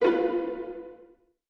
CartoonGamesSoundEffects
Suspicious_v1_wav.wav